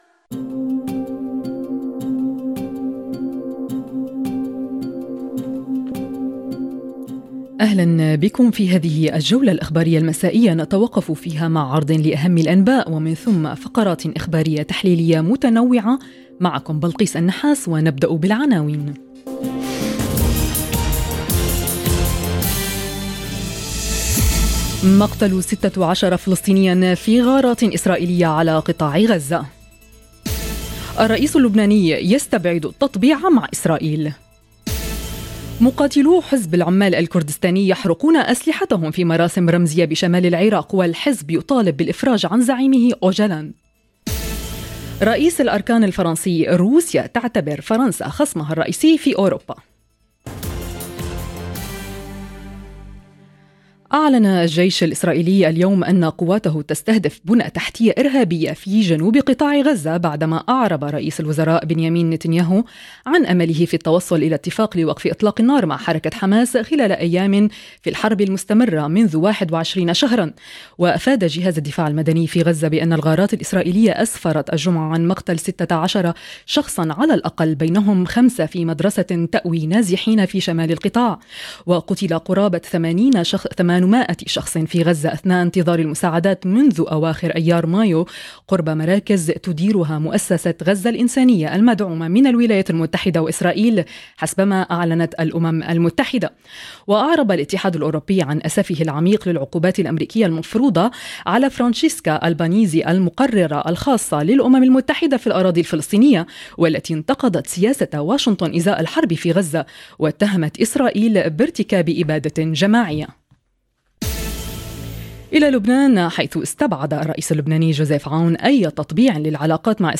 نشرة أخبار المساء 11 7